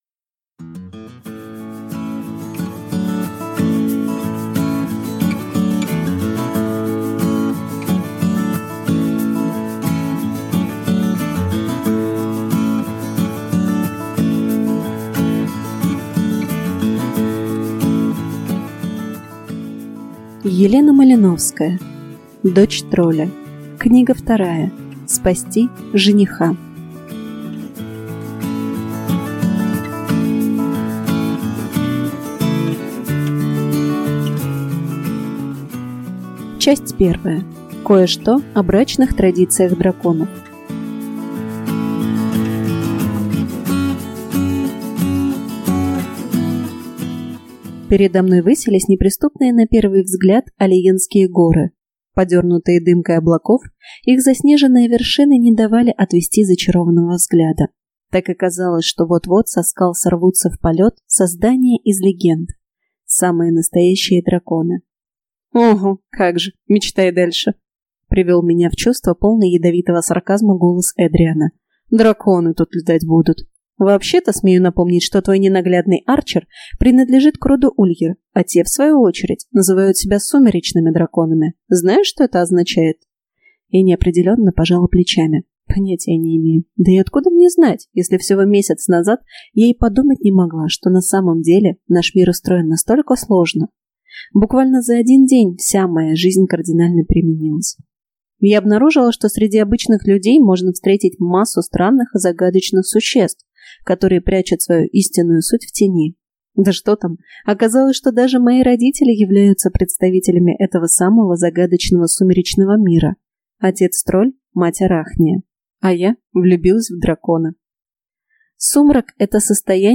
Аудиокнига Спасти жениха | Библиотека аудиокниг